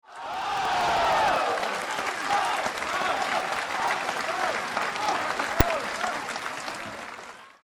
end_cheer_001.mp3